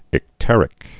(ĭk-tĕrĭk)